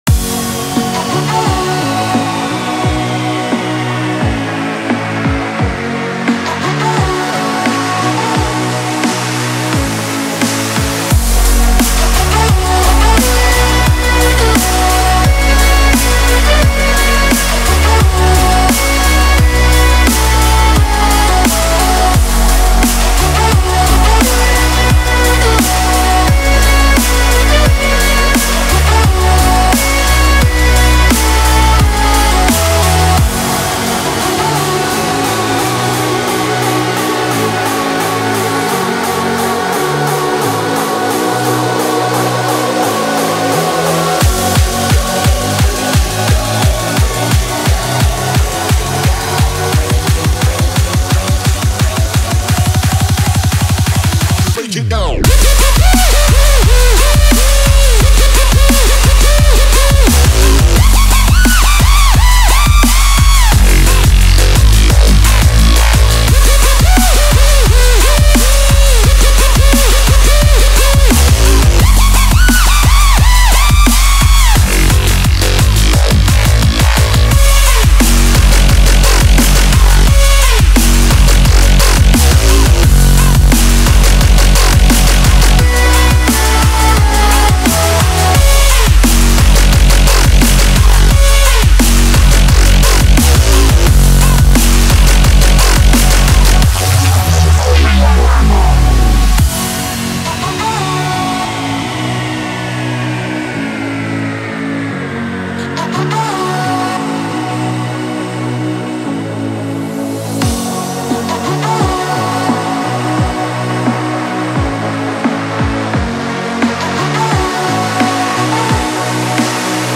Drumstep